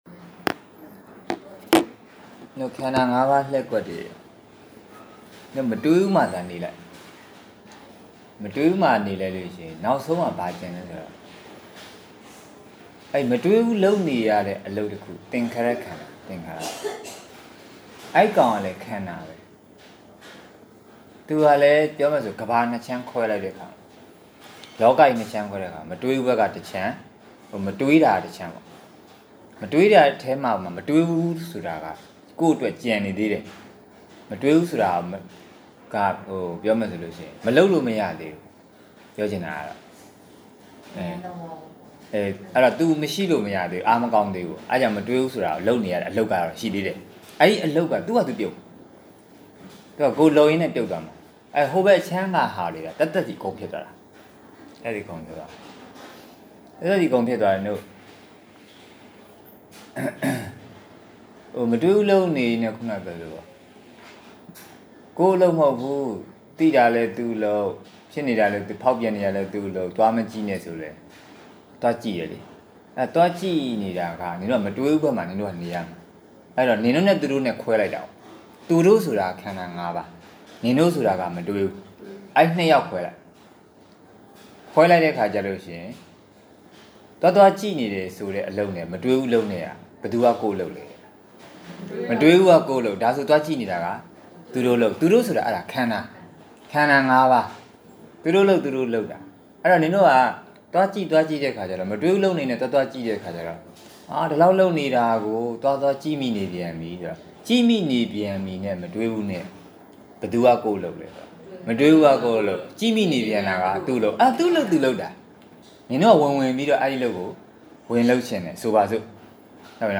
ယောဂီသစ် (၅) ည ဆက် Zoom Online တရားစခန်း (အောက်တိုဘာလ ၂၀၂၄) – ဝိမုတ္တိဉာဏဓမ္မ တရားရိပ်သာ